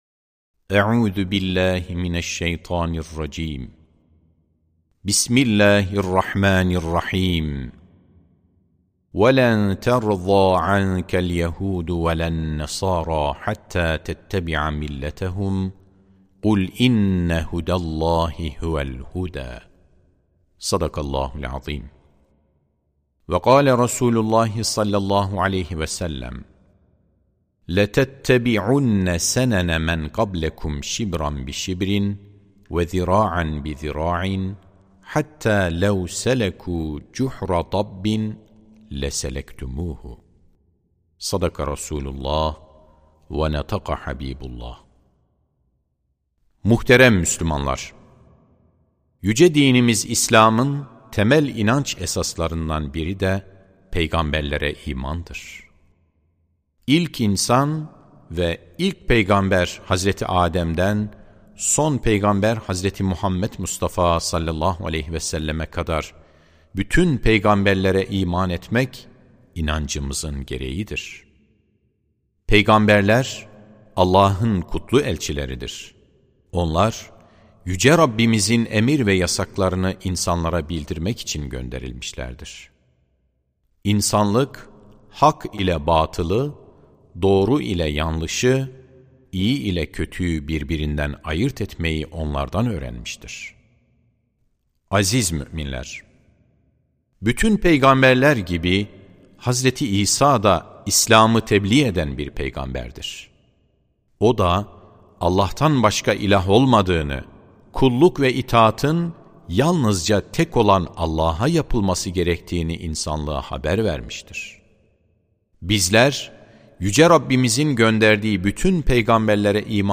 Sesli Hutbe (Milli ve Manevi Değerlerimizi Muhafaza Edelim).mp3